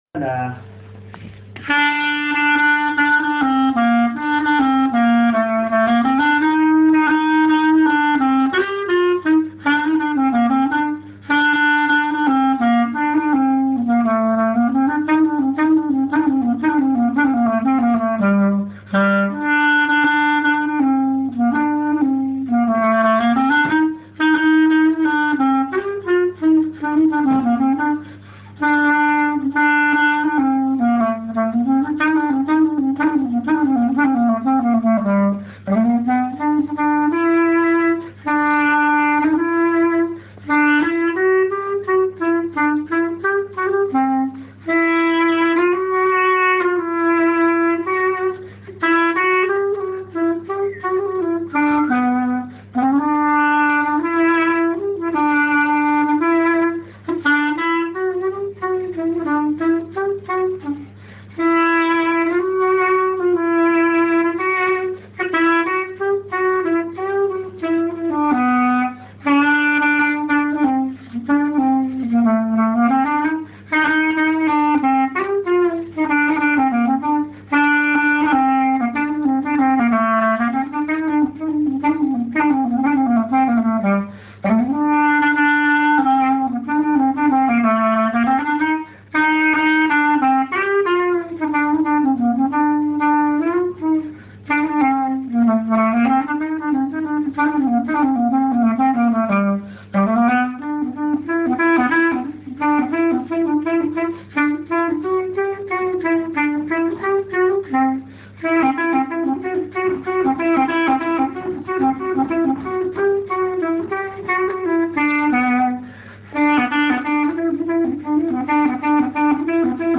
valse.mp3